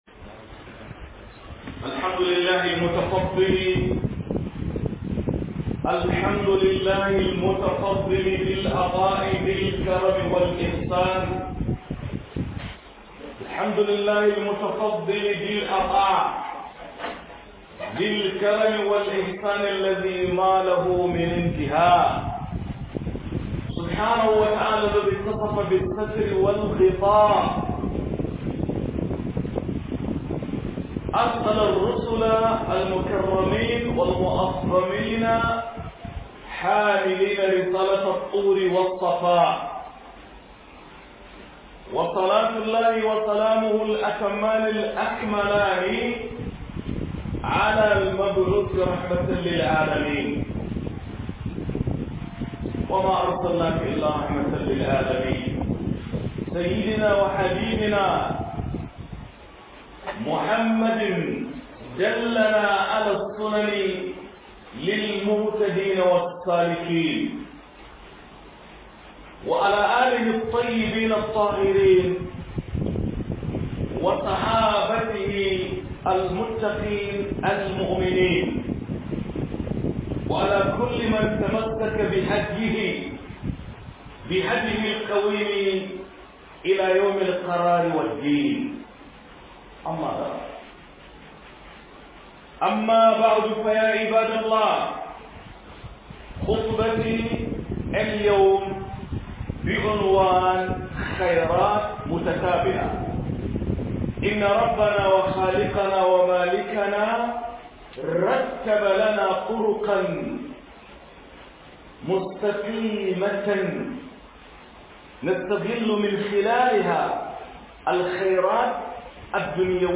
ISTIQAMA - HUDUBA